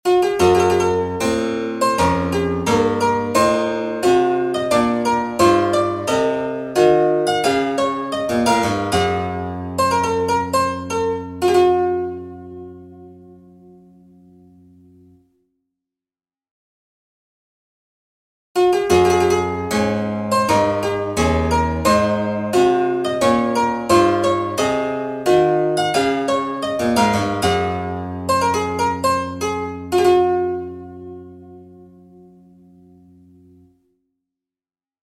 Oneiro_Baroque_Exercises_18edo.mp3